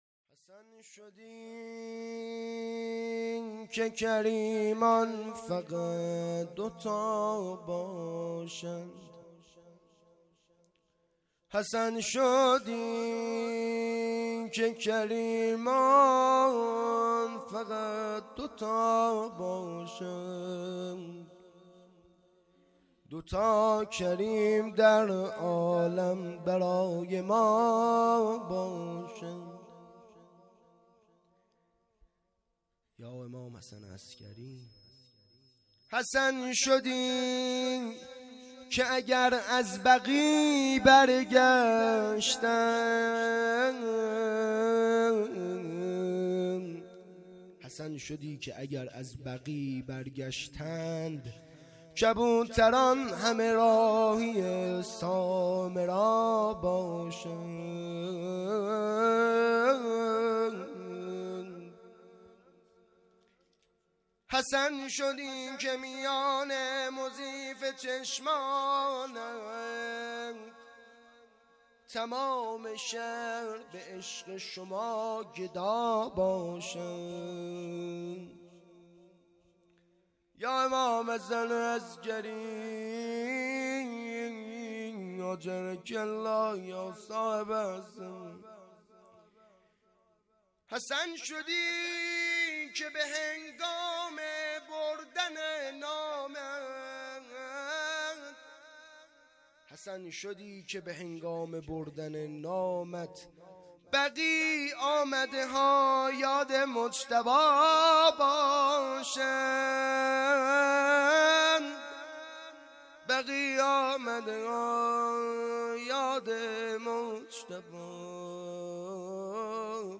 چهارشنبه ۲۱ مهرماه ۱۴۰۰ - هیئت جوانان ریحانه الحیدر سلام الله علیها